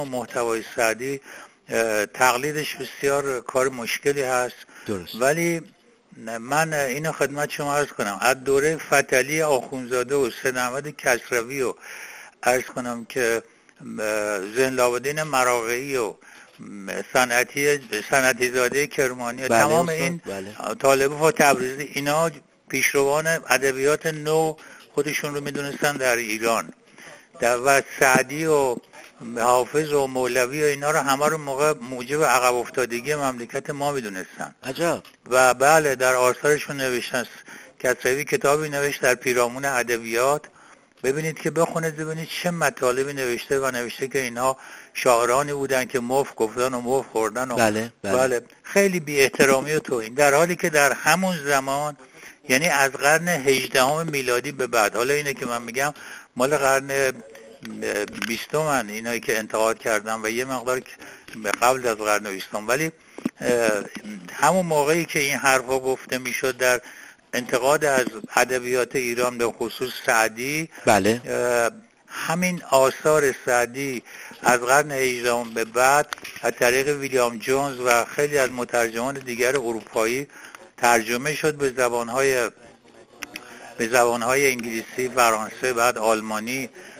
روزگار و زمانه سعدی در گفت‌وگوی ایکنا